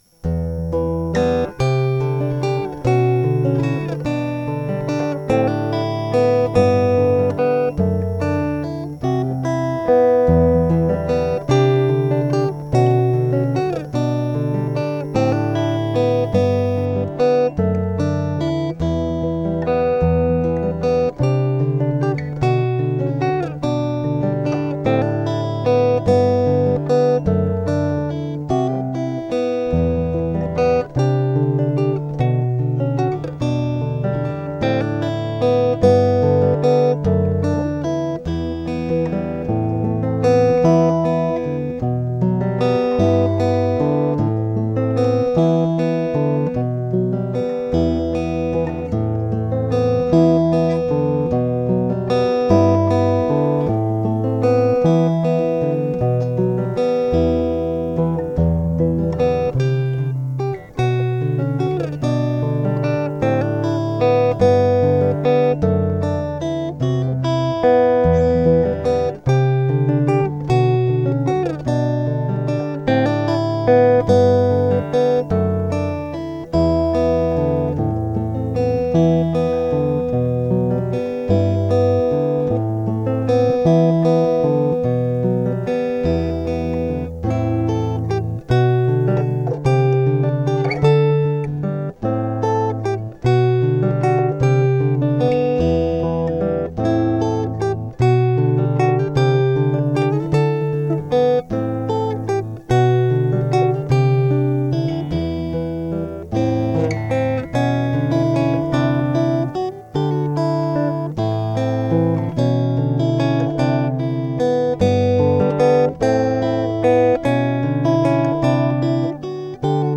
Gitarre